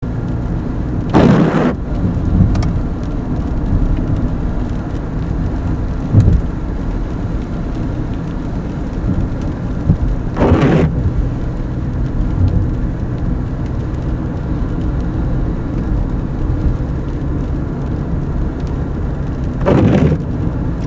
It’s raining today…
enough that I need the wipers,
from making that awful sound.
that-horrible-sound-the-wipers-make.mp3